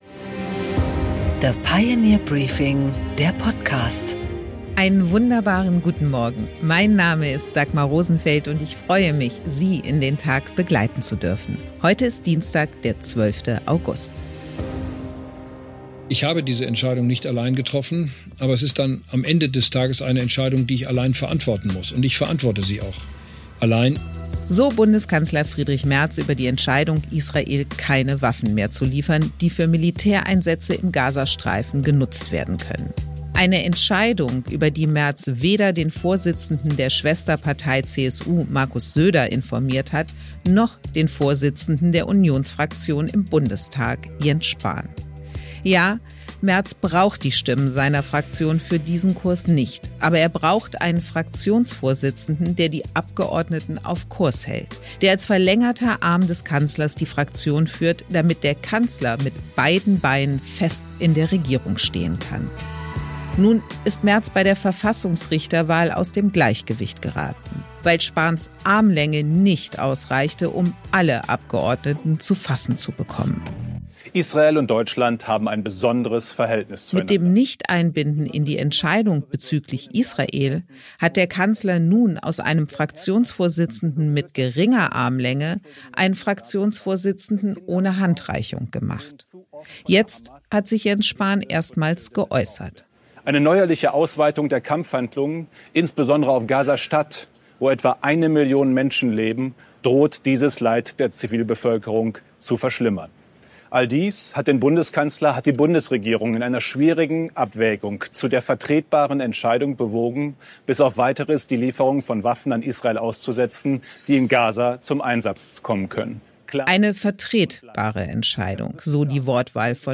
Dagmar Rosenfeld präsentiert das Pioneer Briefing